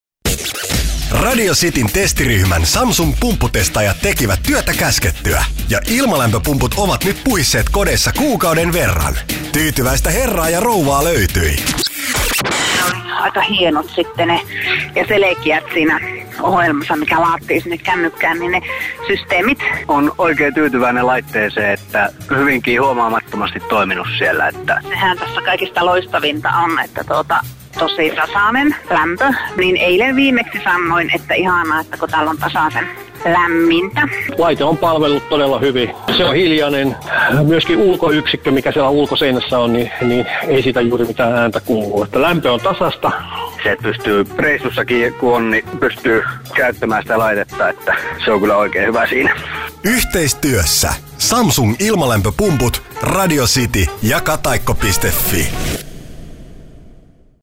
Kuuntele, mitä mieltä testaajat ovat Samsung Exlusive ilmalämpöpumpusta.